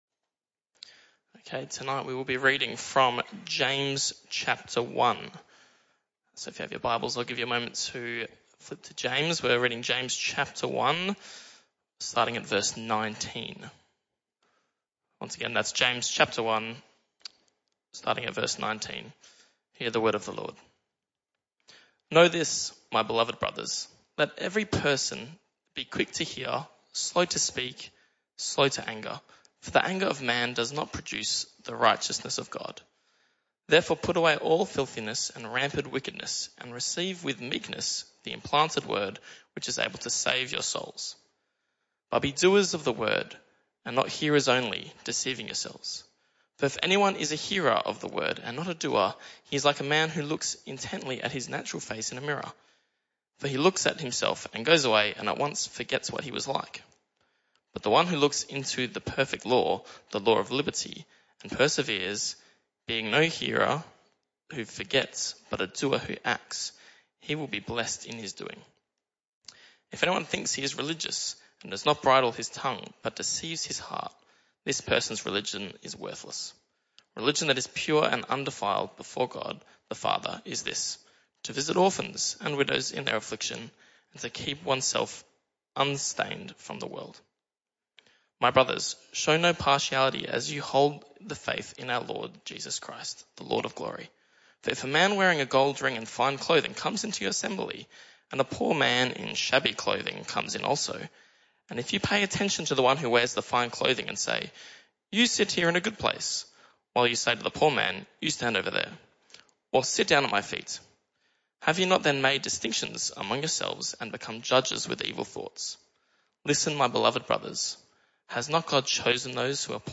This talk was a one-off talk in the PM Service.